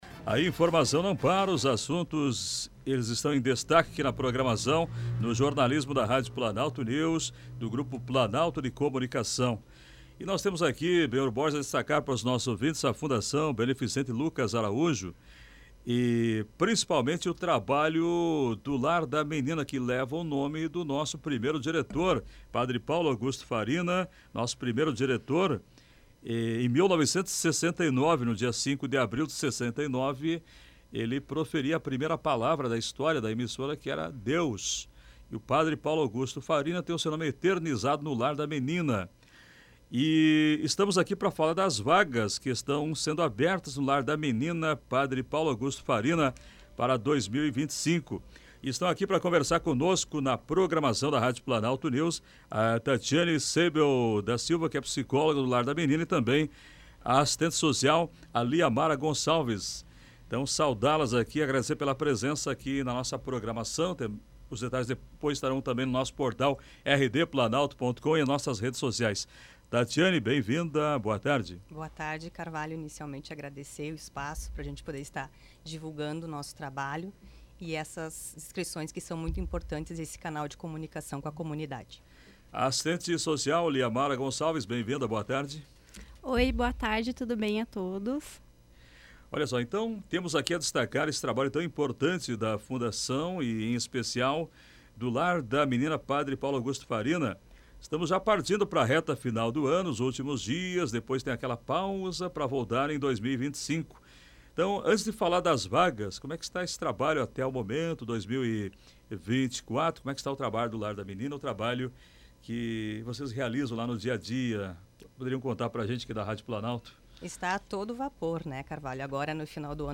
ENTREVISTA-LAR-DA-MENINA-26-11.mp3